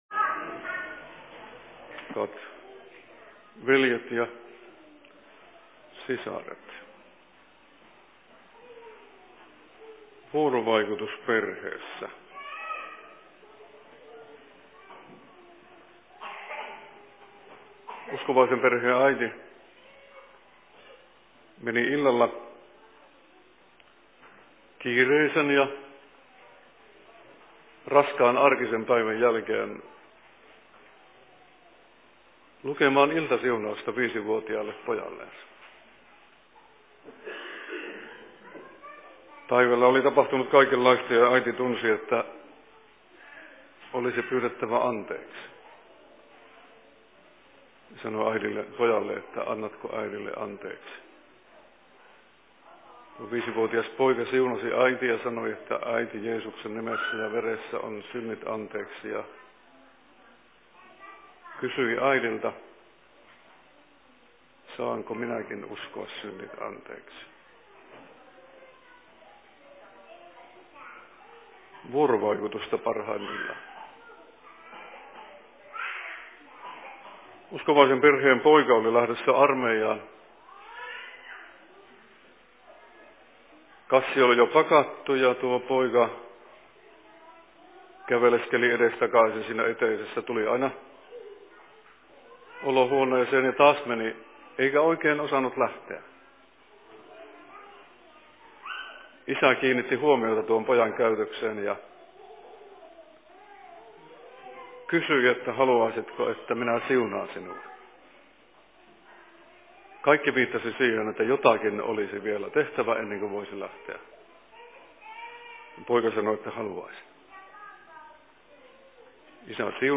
Seurakuntapäivä/Alustus 16.11.2014
Paikka: Rauhanyhdistys Leppävirta